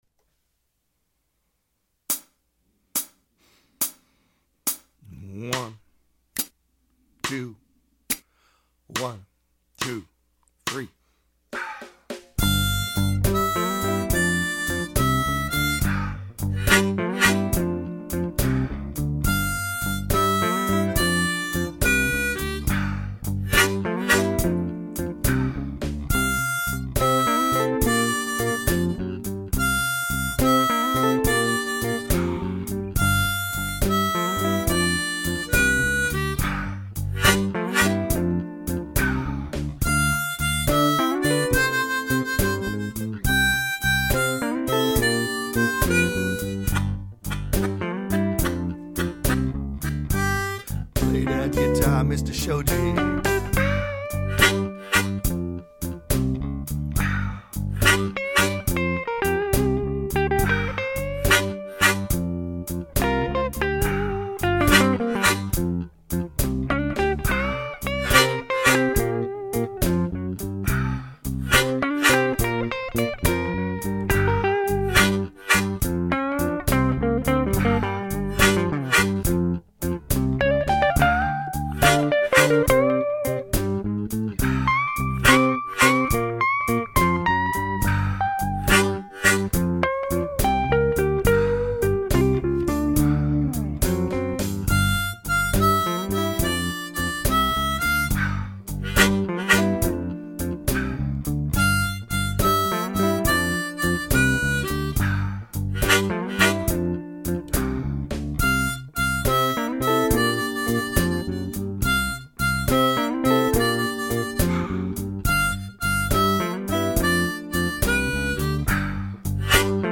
Acoustic at 70bpm